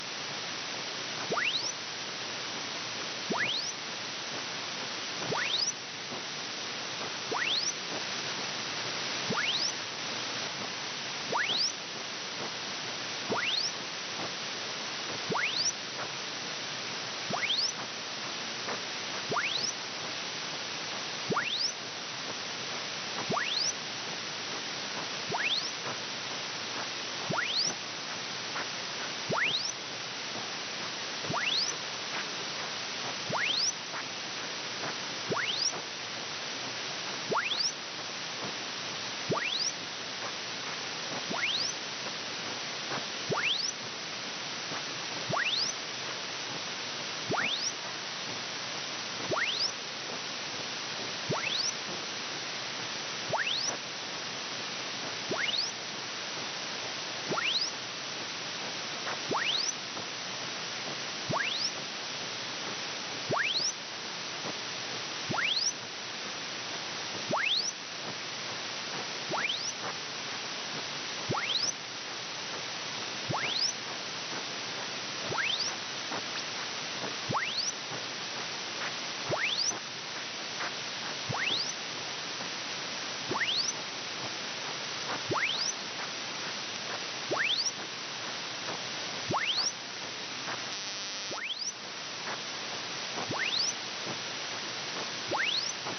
haarp_eastidaho.mp3